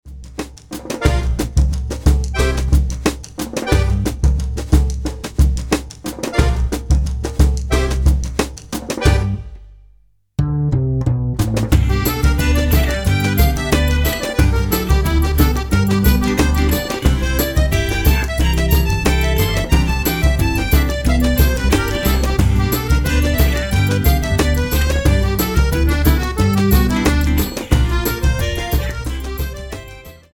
114 BPM